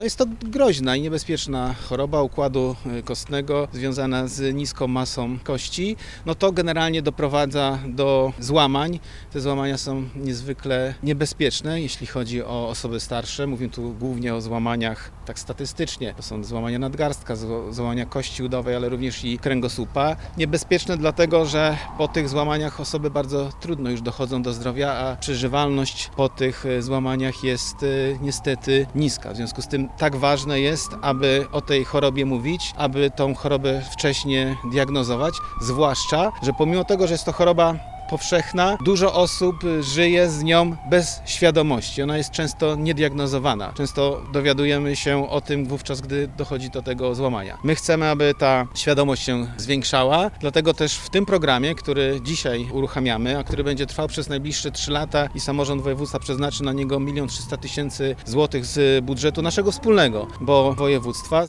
– Co czwarta kobieta po menopauzie jest zagrożona wystąpieniem tej choroby – mówi Marcin Krzyżanowski, wicemarszałek województwa dolnośląskiego, przedstawiając założenia nowego programu.